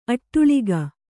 ♪ aṭṭuḷiga